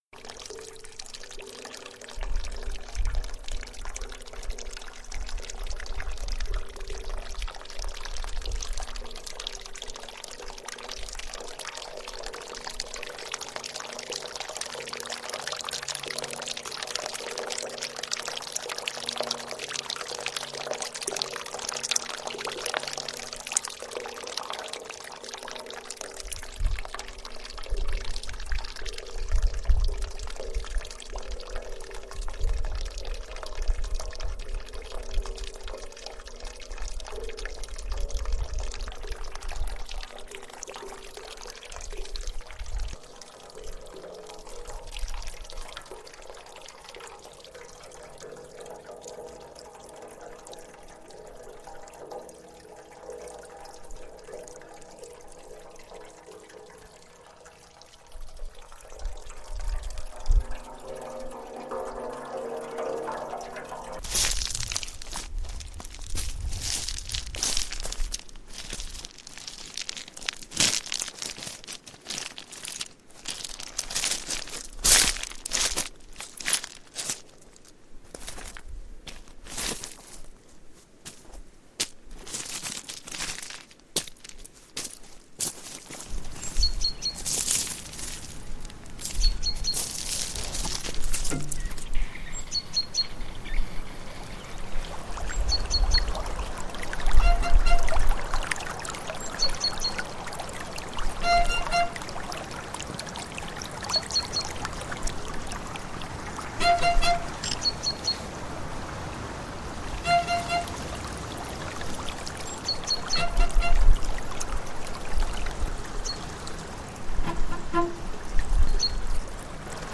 bruits, machines, animaux, violoncelle, piano
installation sonore pour l’expo collective ANIMAL Paris 11